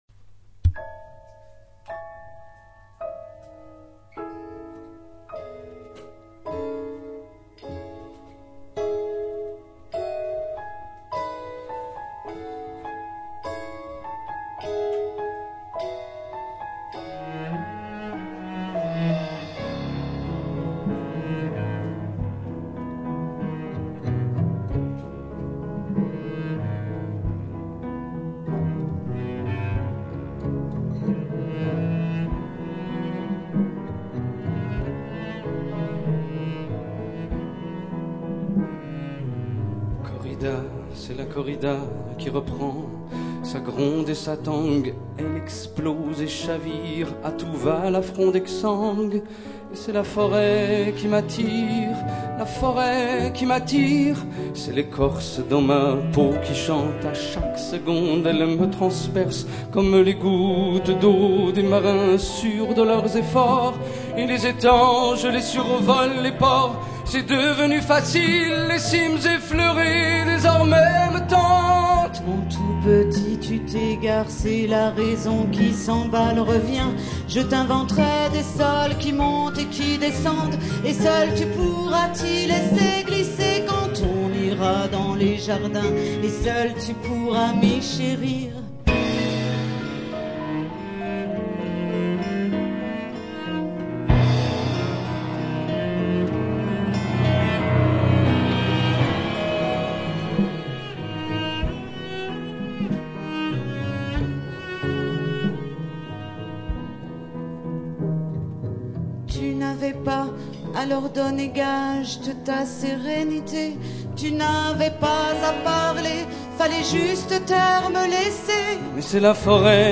chant.
guitare.
violoncelle.
piano
enregistrement live au Sous-Sol.